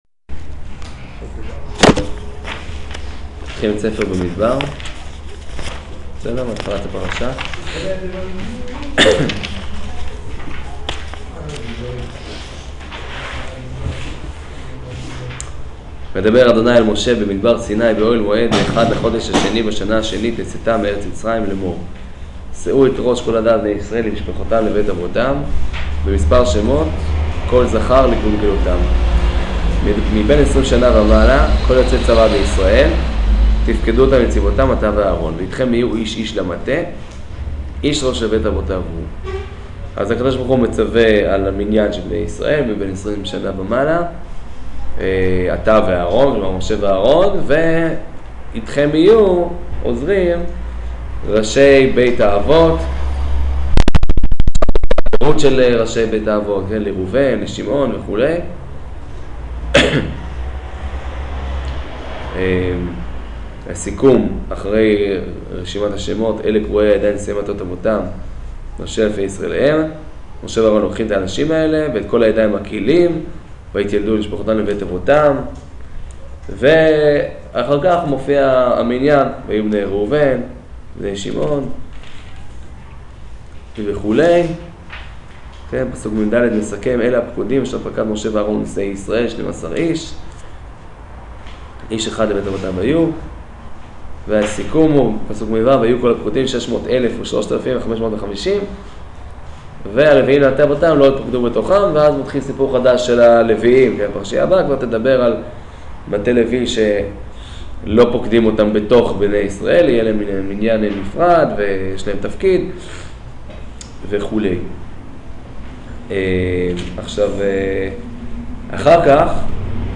שיעור במדבר